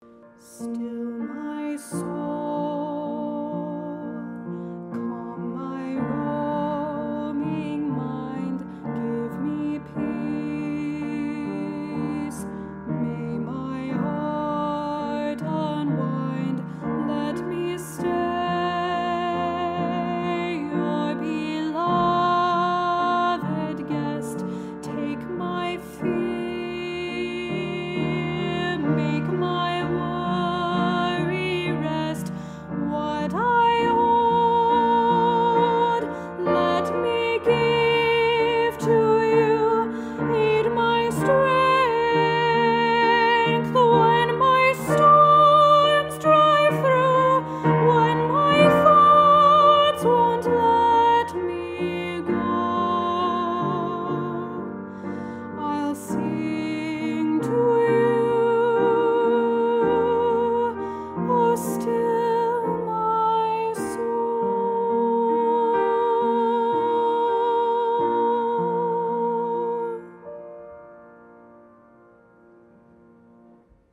unexpected harmonies that still feel warm and soothing